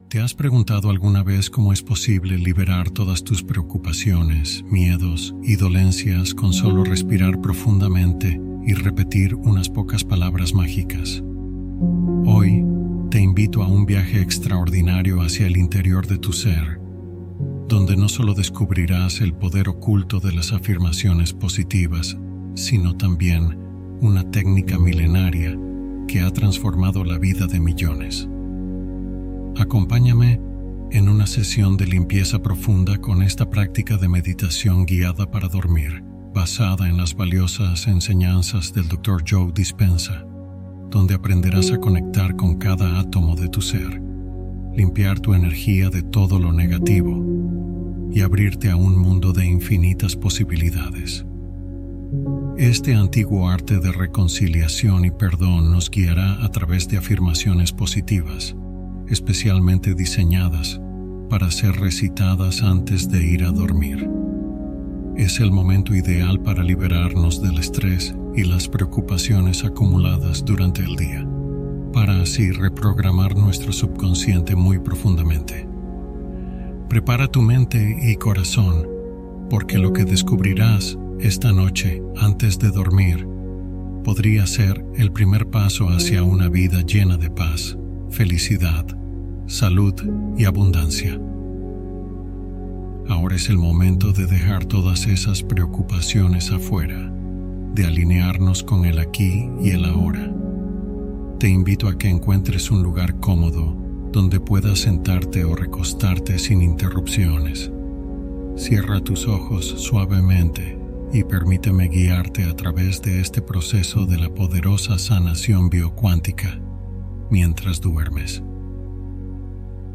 Hipnosis Para Reprogramar Tu Subconsciente y Atraerlo Todo